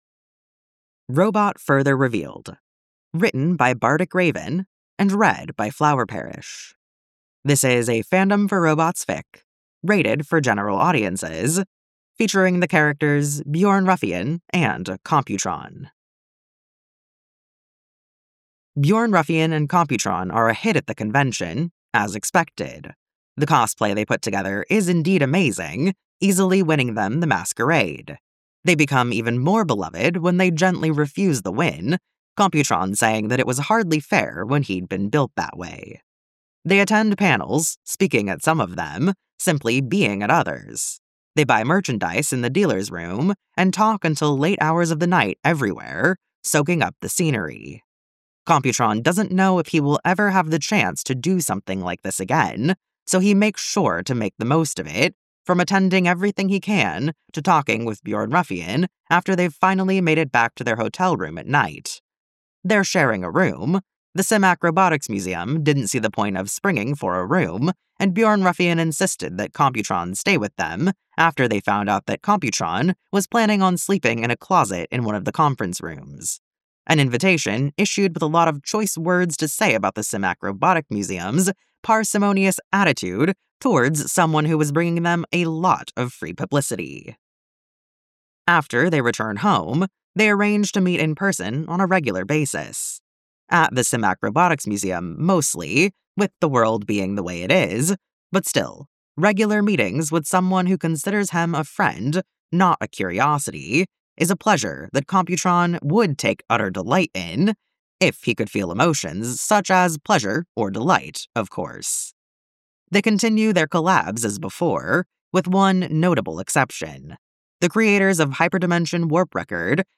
collaboration|two voices